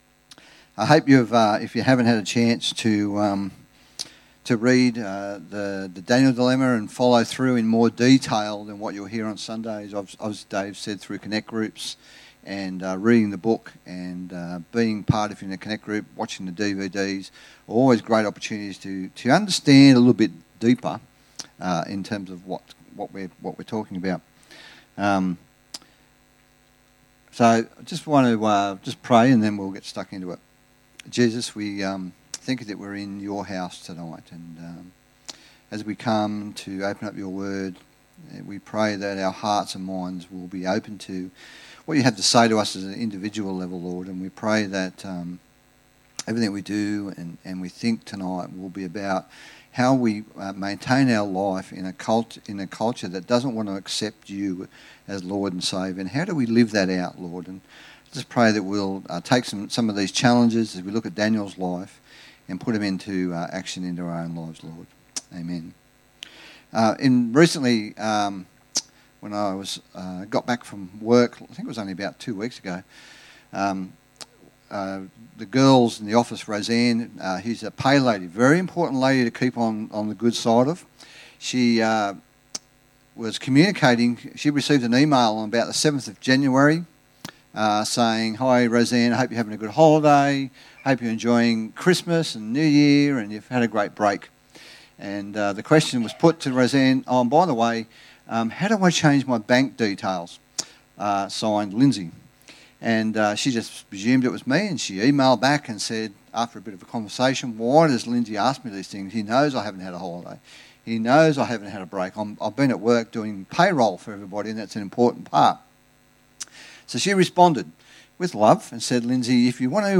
preached at the evening service.